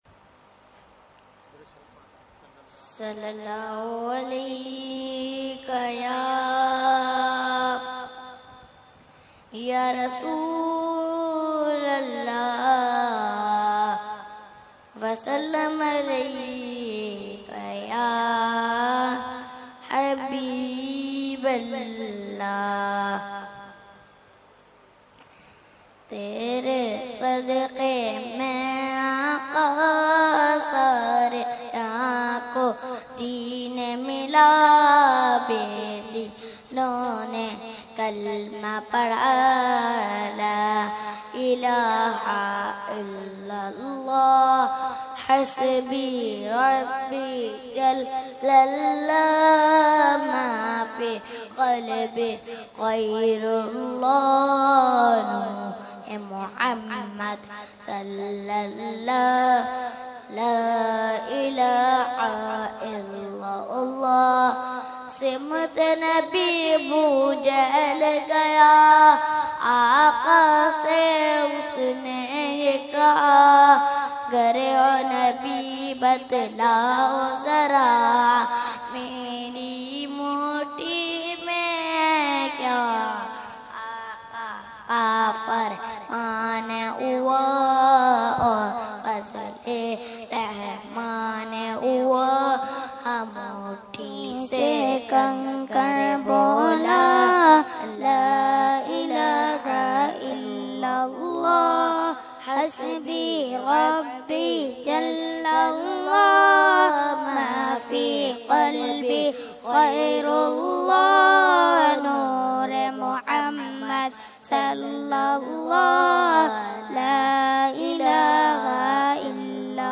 Category : Naat